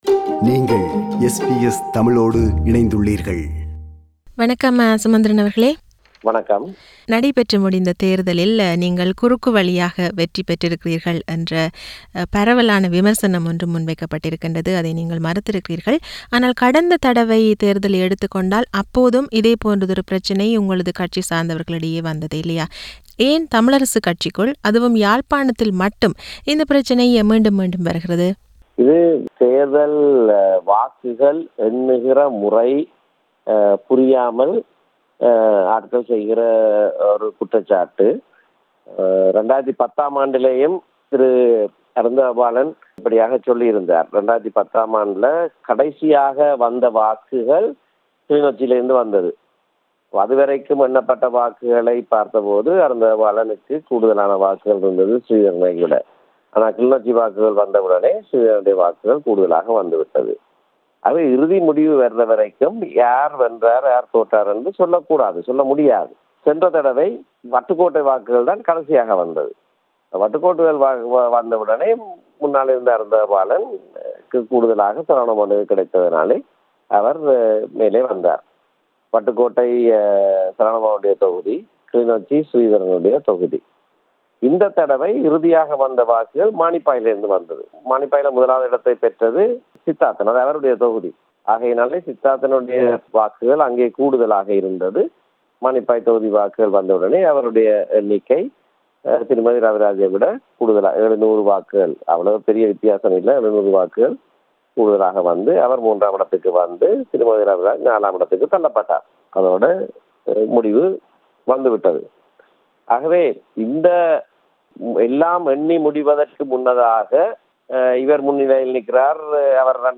In an exclusive interview with SBS Tamil, M.A. Sumanthiran, who won one of TNA’s three seats in Jaffna, admitted that the TNA had suffered a serious setback in its constituency, and called for serious introspection on the party’s political work as well as conduct amid growing differences within.